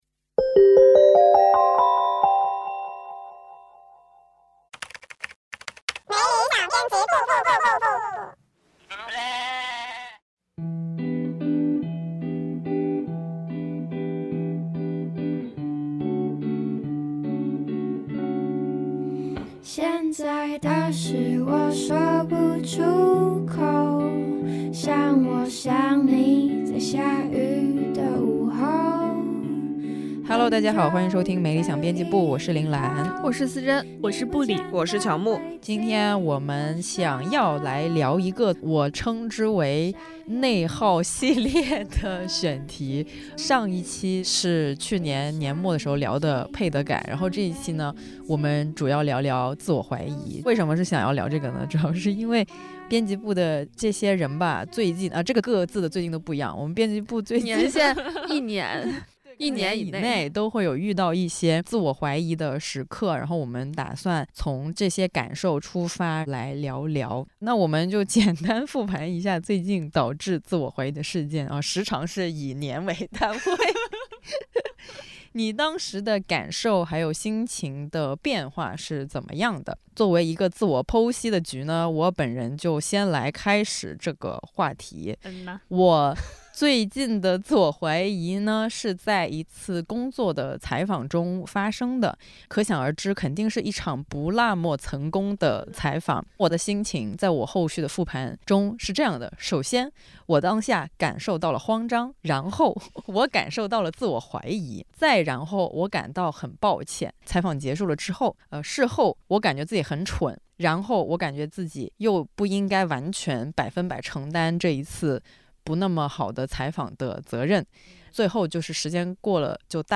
🍬本期节目我们厉害了！很开心请来演员唐嫣一起聊聊天。自从《繁花》里的汪小姐出现后，那句"我是我自己的码头"回荡在很多人的头脑中。